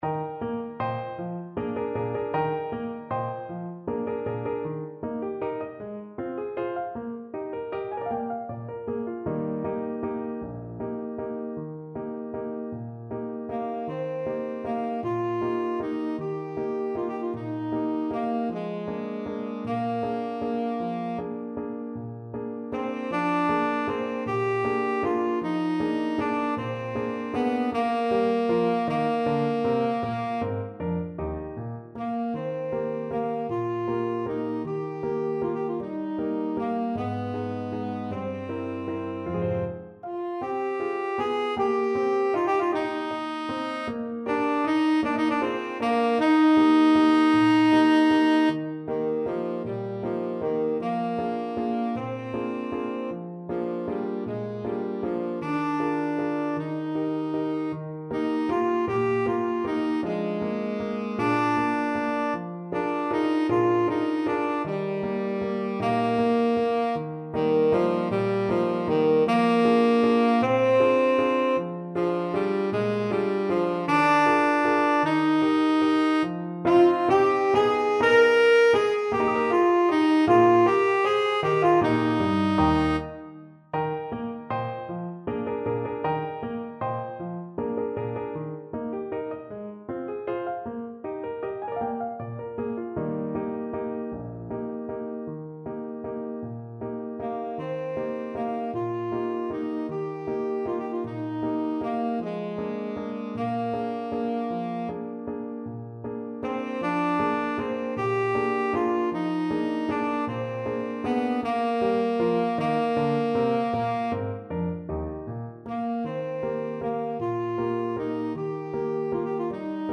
Allegretto . = c.52
6/8 (View more 6/8 Music)
Classical (View more Classical Tenor Saxophone Music)